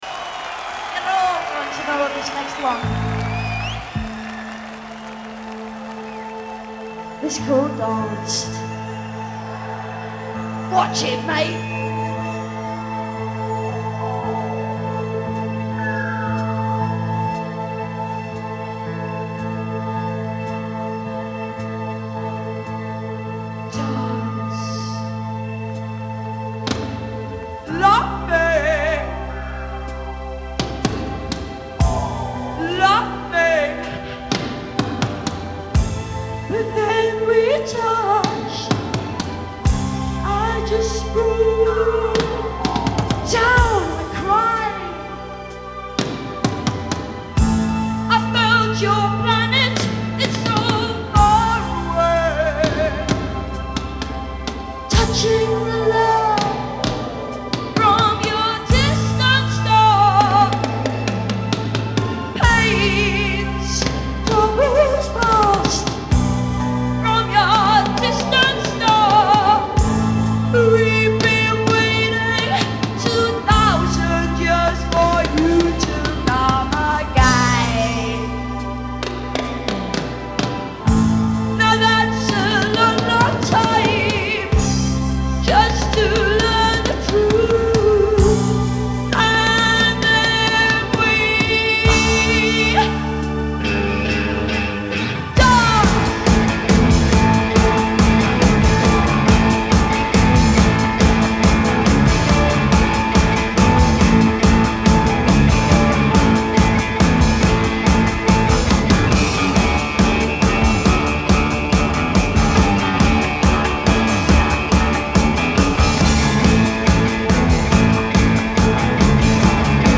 Genre : Punk, New Wave